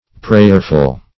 Prayerful \Prayer"ful\, a.